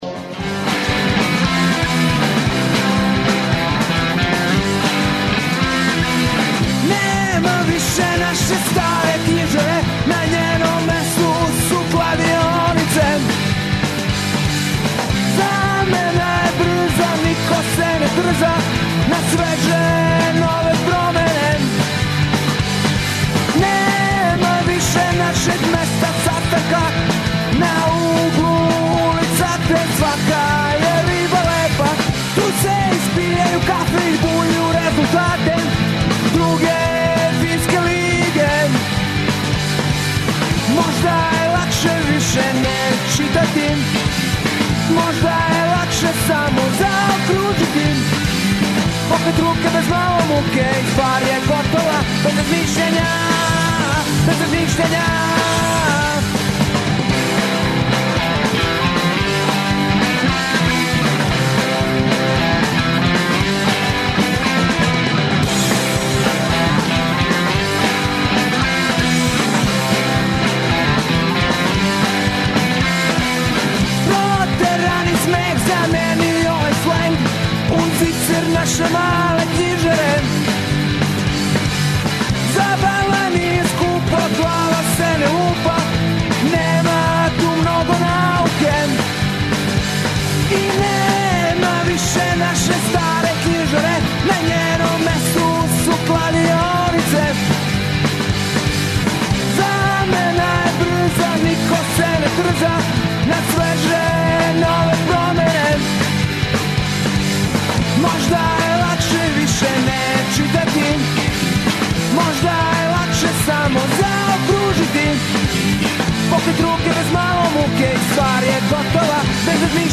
Емисију емитујемо уживо из Бајине Баште, представићемо вам културни живот овога града.